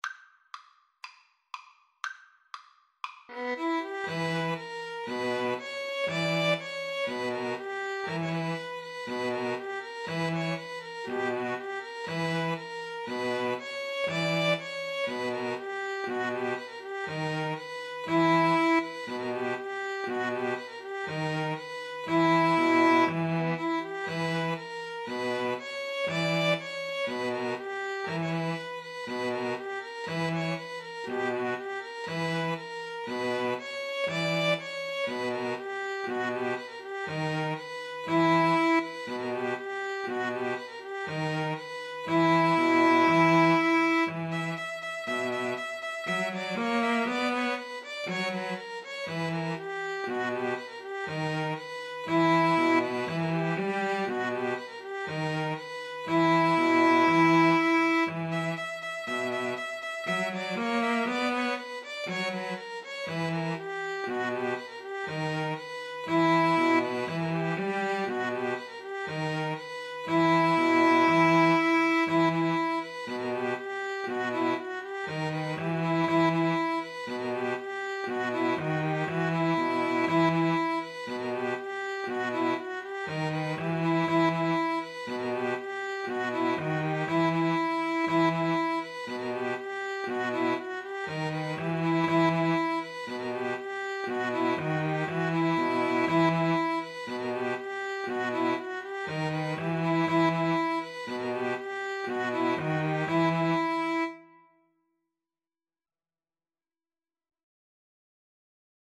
D major (Sounding Pitch) (View more D major Music for String trio )